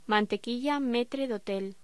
Locución: Mantequilla metre de hotel
voz